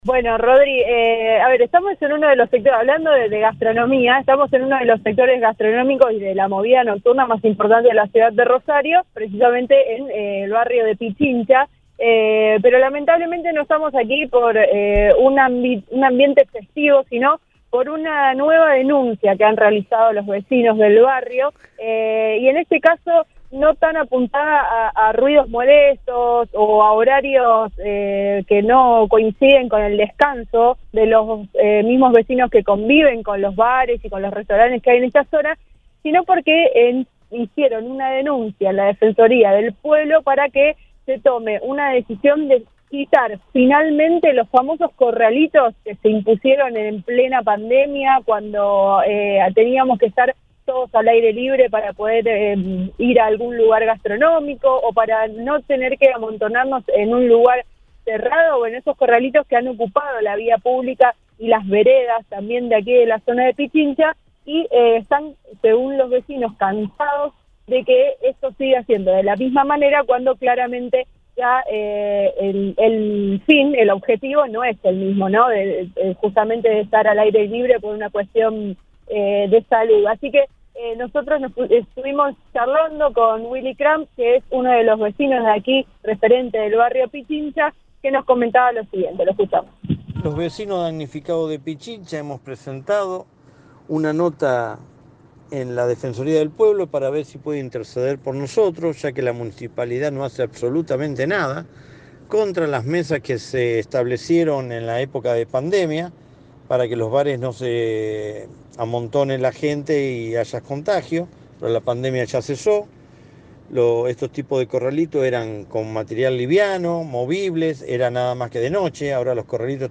Uno de los referentes de este grupo de vecinos le dijo al móvil de Cadena 3 Rosario que “hemos presentado una nota para que puedan interceder por nosotros porque la municipalidad no hace nada”.